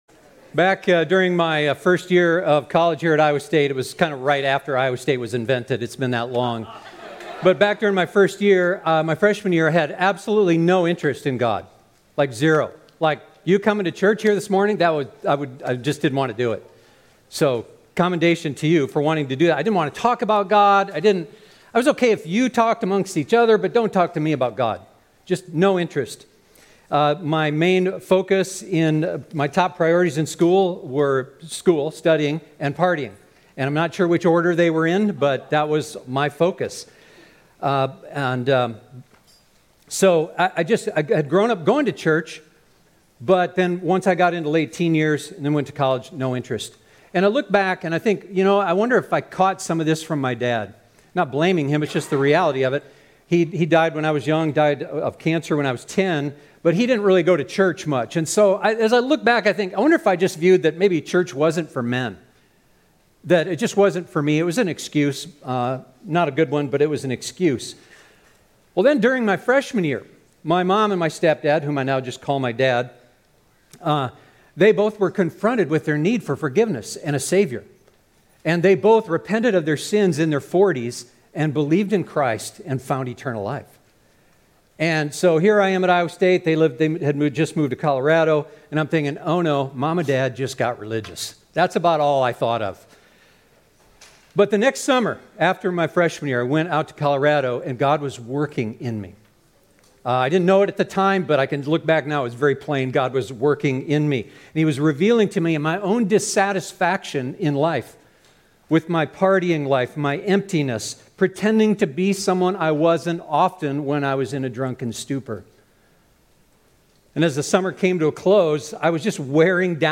This Sunday we begin a new sermon series diving into the great story of the Savior of the world, the Gospel of Jesus Christ, written by a man named Luke.